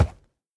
horse_wood3.ogg